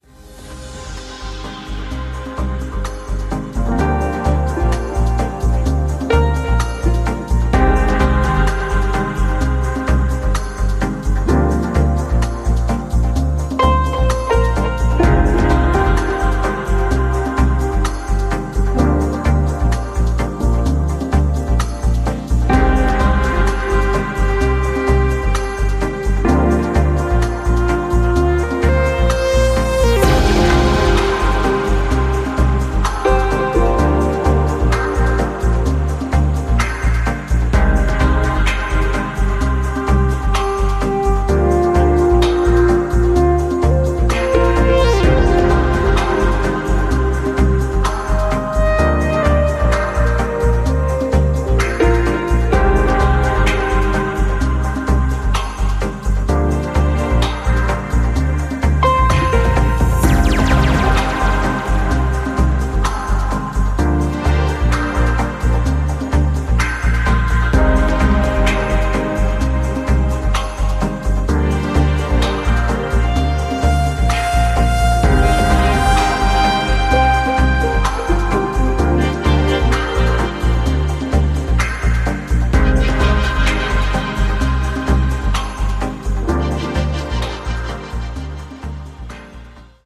彼方までダブワイズしていくような